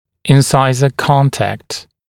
[ɪn’saɪzə ‘kɔntækt][ин’сайзэ ‘контэкт]резцовое смыкание, контакт между резцами